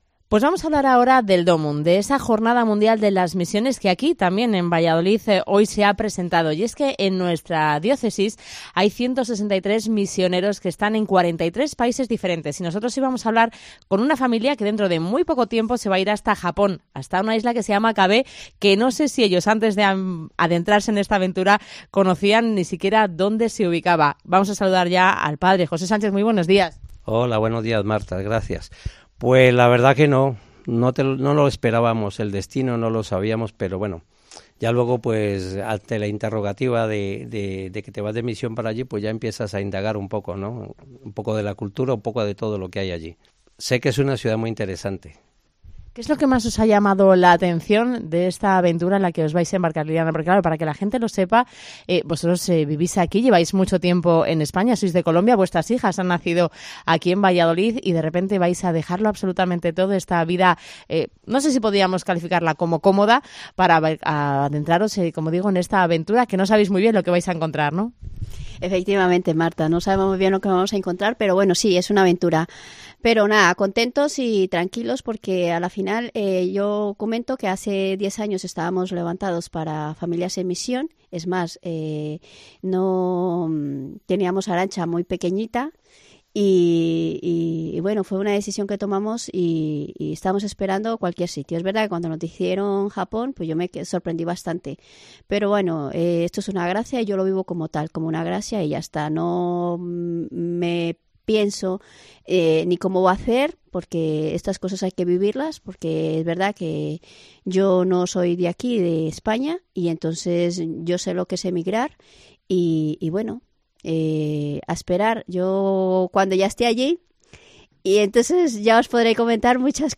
Hoy han relatado su epxriencia en el Mediodía de COPE en Valladolid.